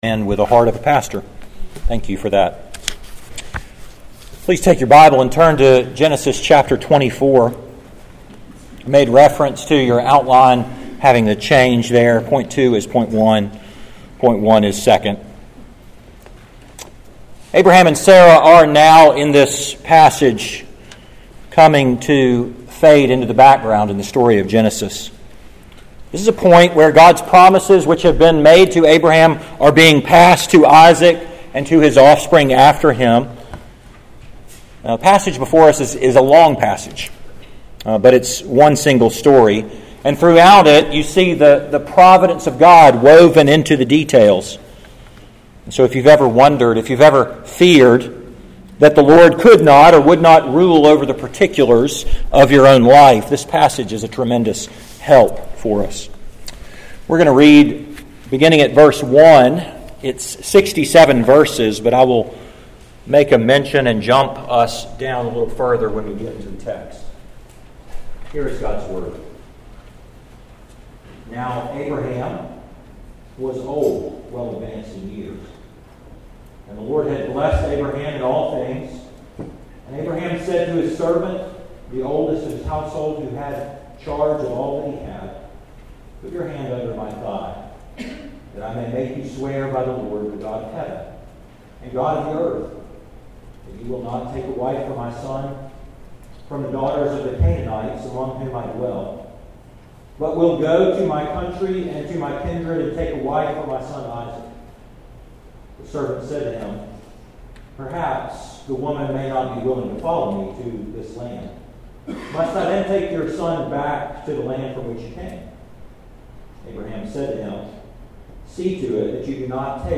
Bible Text: Genesis 24 | Preacher